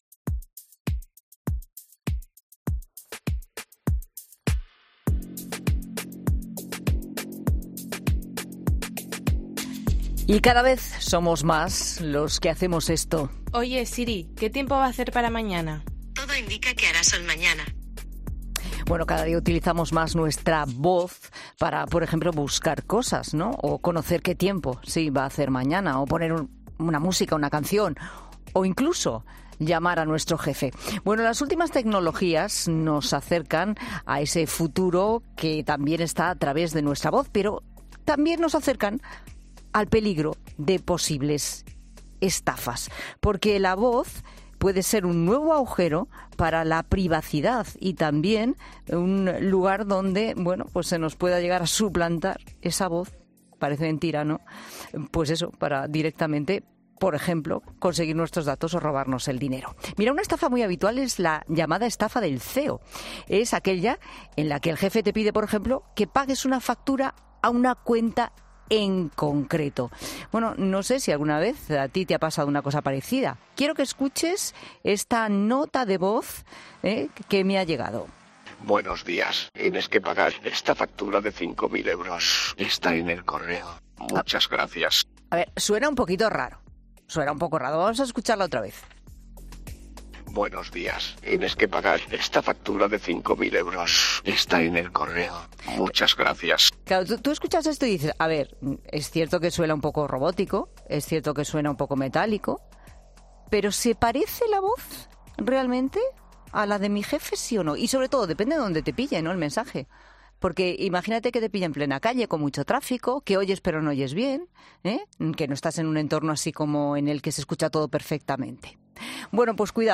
El experto ha explicado en ‘La Tarde’ en qué consiste la biometría.